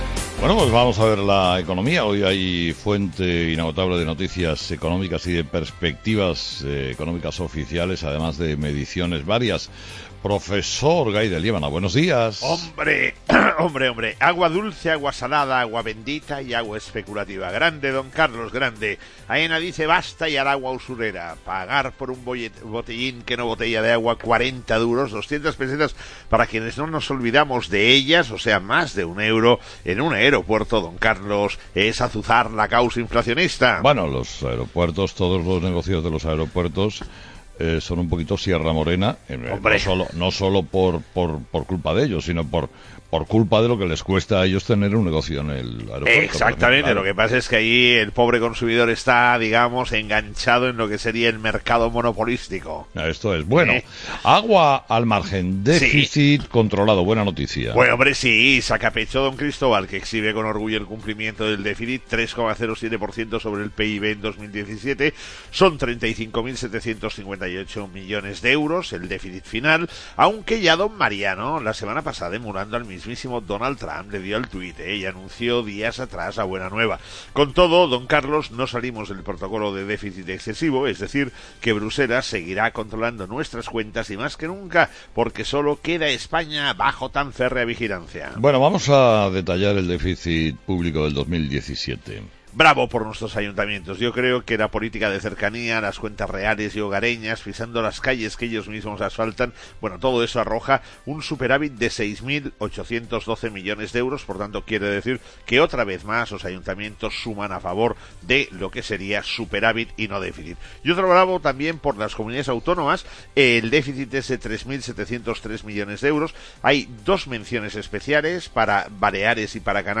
Todas las mañanas la actualidad económica en 'Herrera en COPE' con el profesor Gay de Liébana.